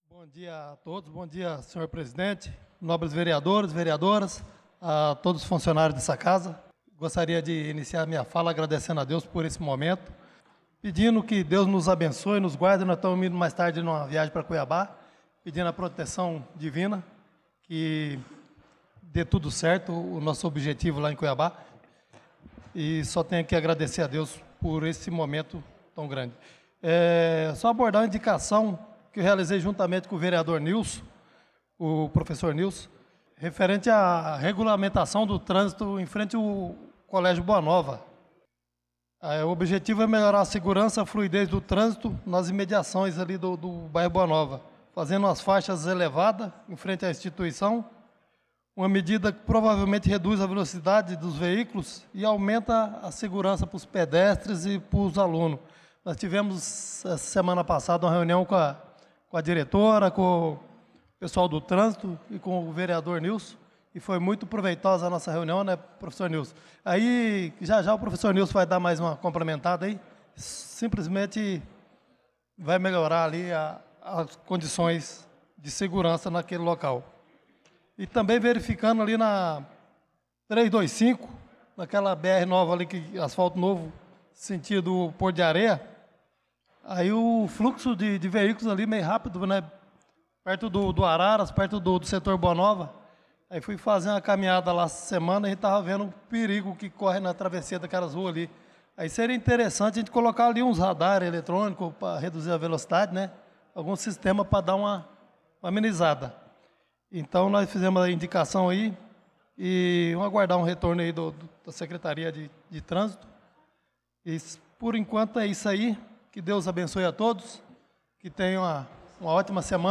Pronunciamento do vereador Chicão Motocross na Sessão Ordinária do dia 18/03/2025